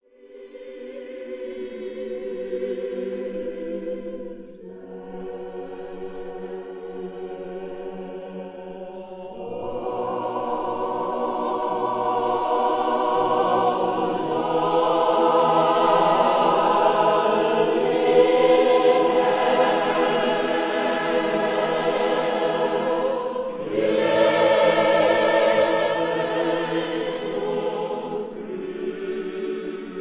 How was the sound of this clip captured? Catalonia, Spain